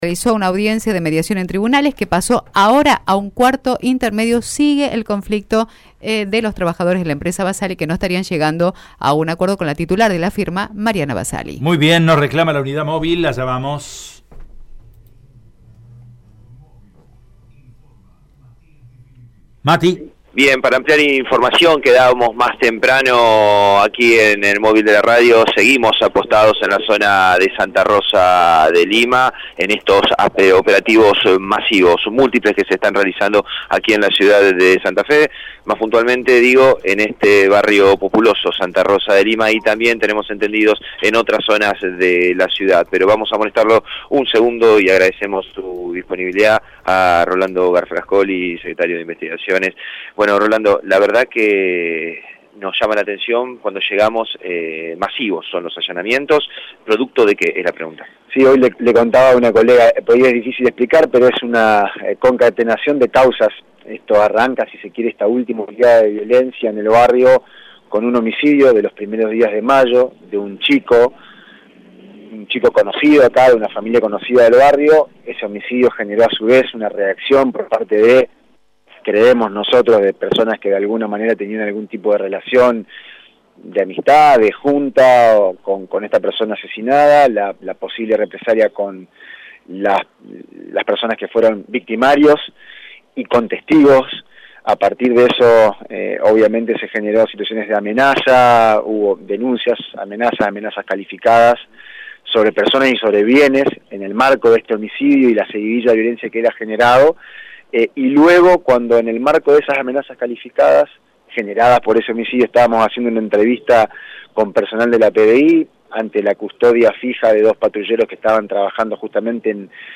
Rolando Galfrascoli, secretario de Investigación Criminal explicó que los trabajos se llevan a cabo en el marco de una causa por abuso de armas ocurrido días atrás.
AUDIO ROLANDO GALFRASCOLI EN RADIO EME: